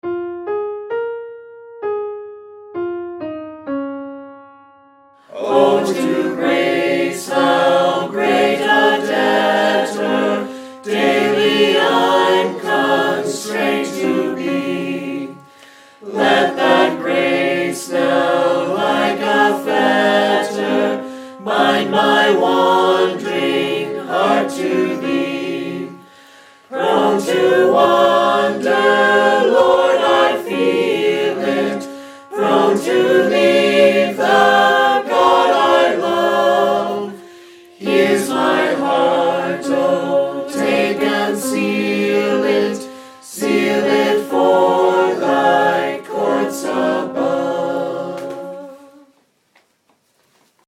The files below use a recording made at our retreat in July, when a number of us learned the harmonies for this hymn.
The harmony part is always illustrated in the left channel by a piano.  Each file begins with a piano lead in that gives the final phrase of the melody so you can practice making the transition to whatever harmony part you’ve chosen.
Come-Thou-Fount-Alto.mp3